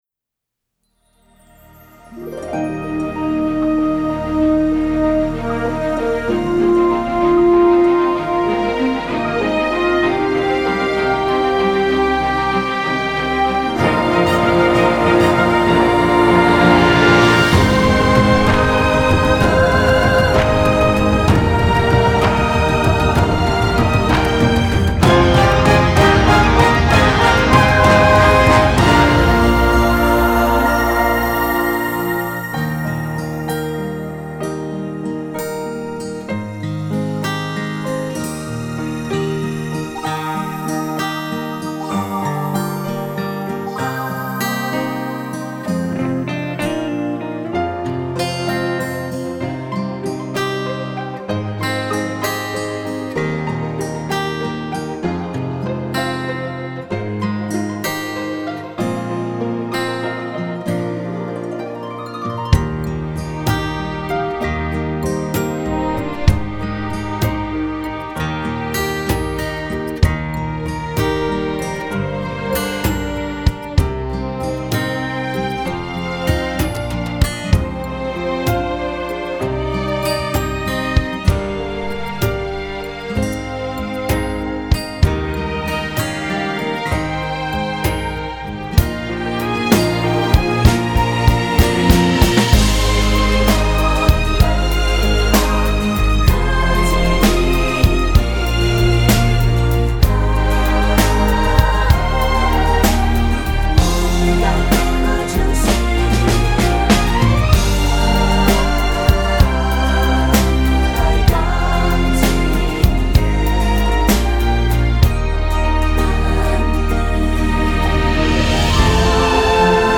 在整体音乐制作上，将流行元素融入其中，交响与流行的结合使歌曲更具时代感，易于传播和传唱。